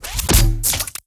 GUNArtl_Grenade Launcher Fire_05.wav